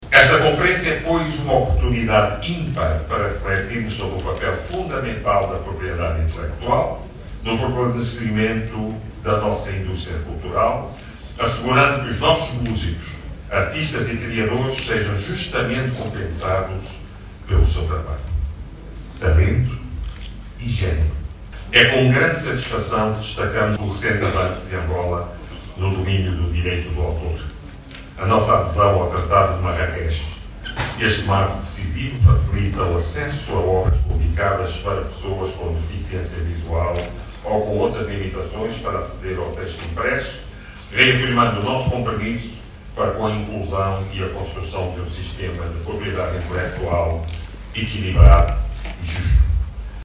Em debate estão temas ligados a estruturas e acordos internacionais de Propriedade Intelectual, monetização da música e da economia digital, bem como o impacto da inteligência artificial. Na abertura da conferencia o Ministro da Cultura, Filipe Zau, valorizou esta iniciativa e destacou o funcionamento do centro de analise de Angola no domínio do direito que tem facilitado o acesso de obras publicadas á pessoas com necessidades especiais.